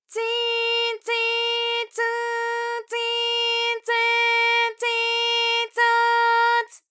ALYS-DB-001-JPN - First Japanese UTAU vocal library of ALYS.
tsi_tsi_tsu_tsi_tse_tsi_tso_ts.wav